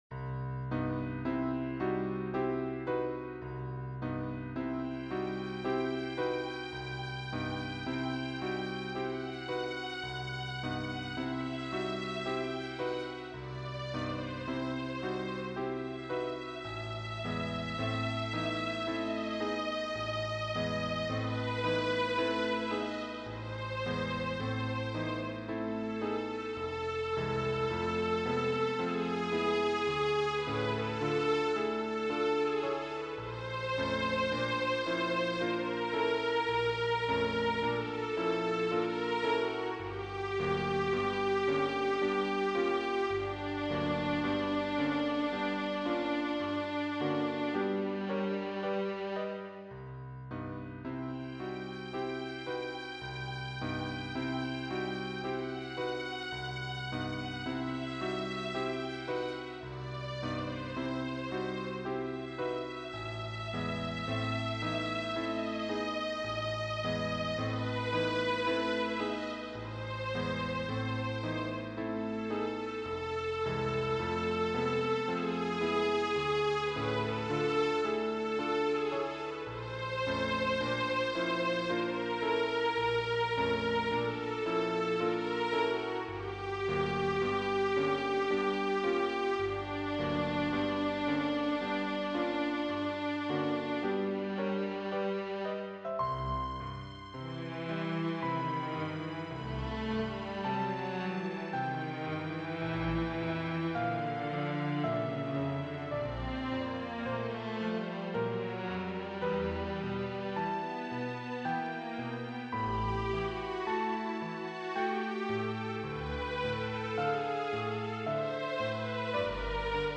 for viola and piano